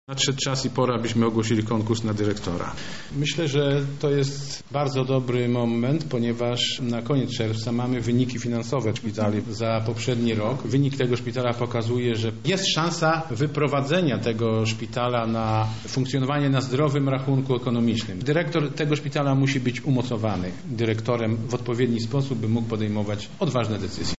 O powodach tej decyzji, mówi marszałek Sosnowski